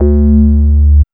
2808R BASS.wav